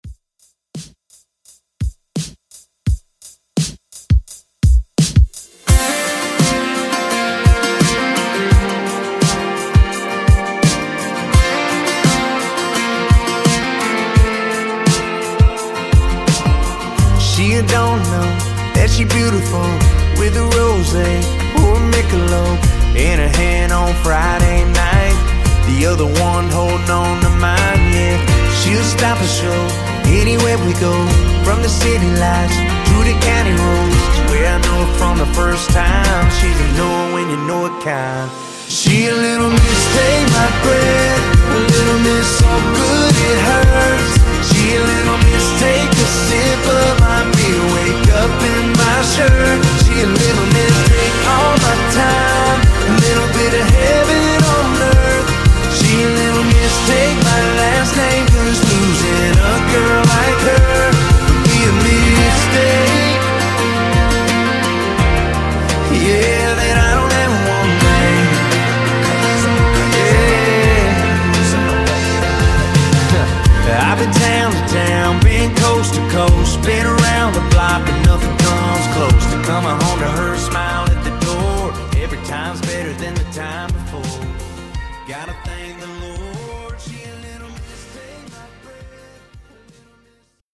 Genre: DANCE
Clean BPM: 120 Time